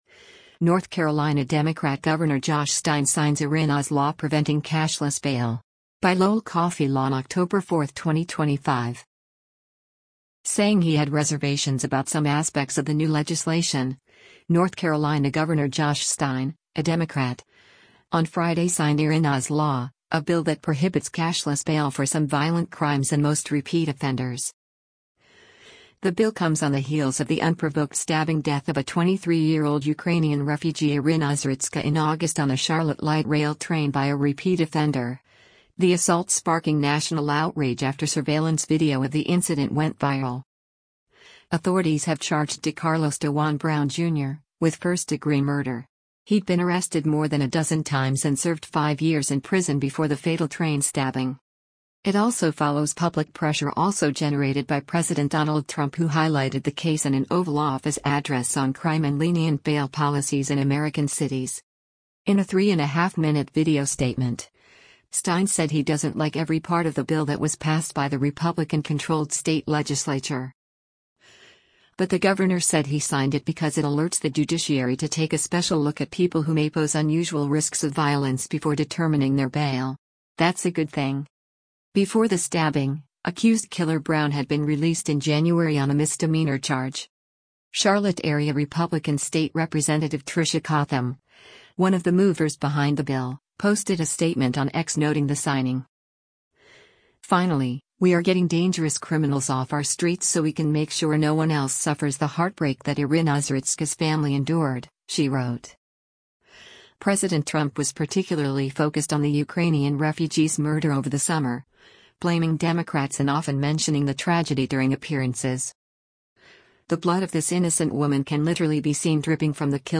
In a three-and-a-half minute video statement, Stein said he doesn’t like every part of the bill that was passed by the Republican-controlled state legislature.